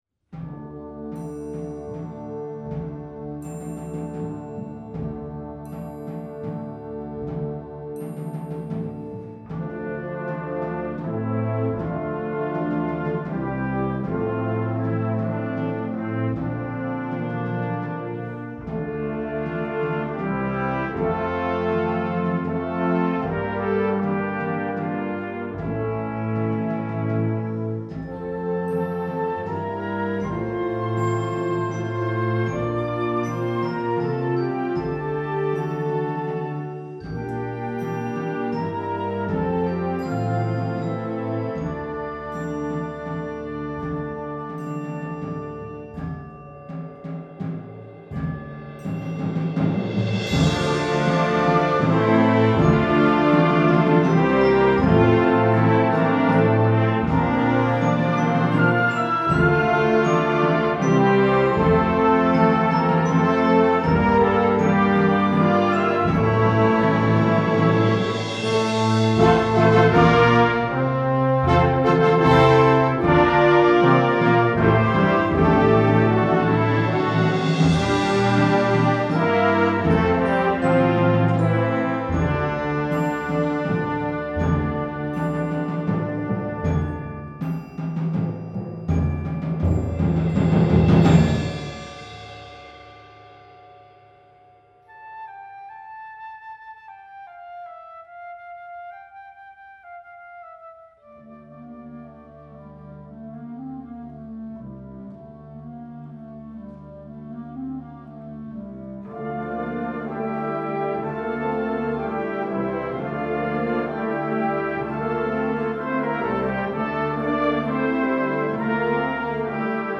Gattung: Jugendwerk für Flexible Band/String Ensemble
Besetzung: Blasorchester